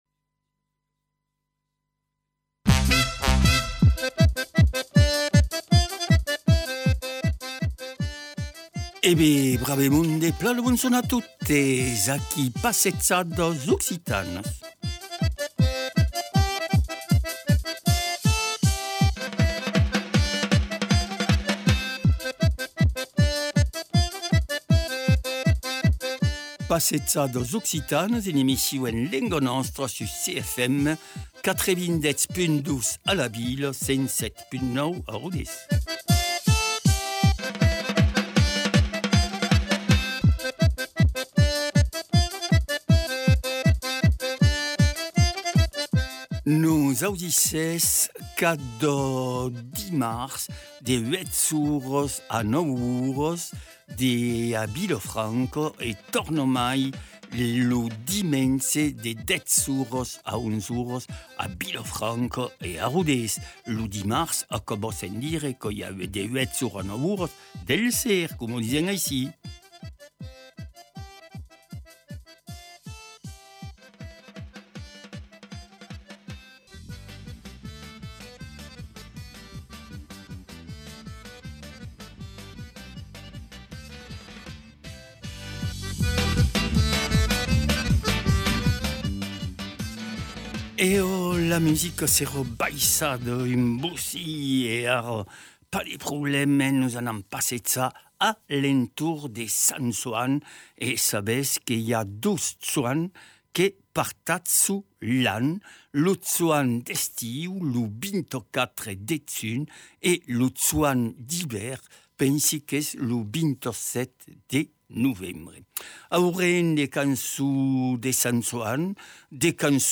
Cants e Musicas a l’entorn de Sant Joan e dels dalhaires.